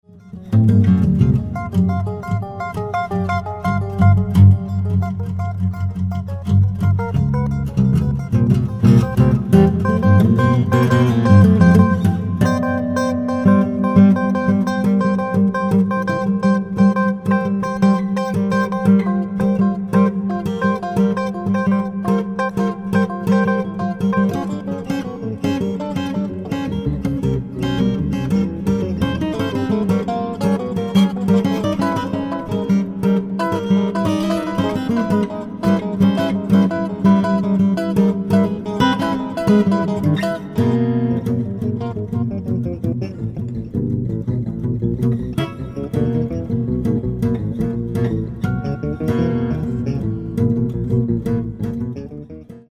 Electric guitar, Samples